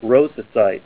Say ROSASITE